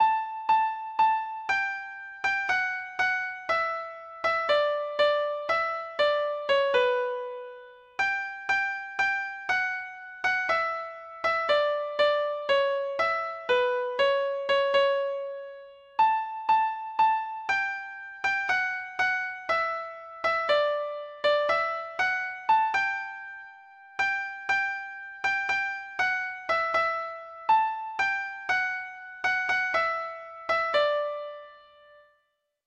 Folk Songs from 'Digital Tradition'
Traditional Music of unknown author.